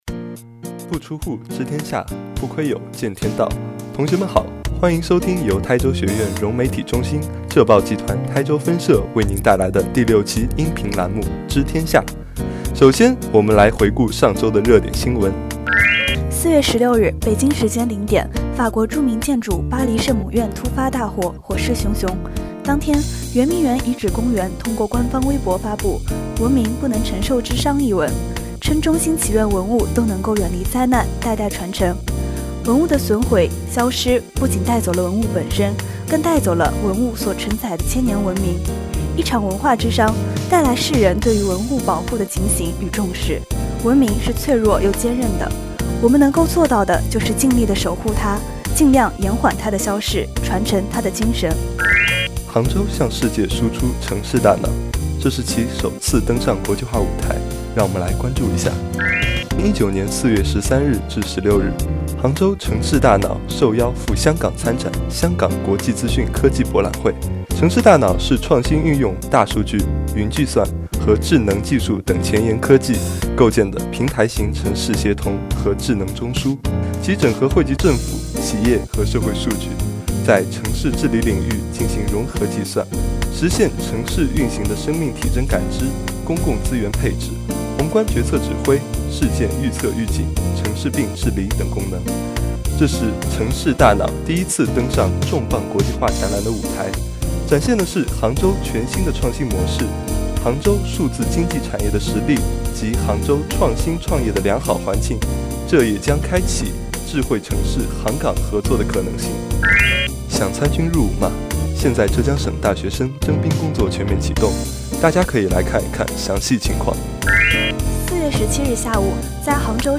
知天下︱台州学院融媒体中心音频播报第六期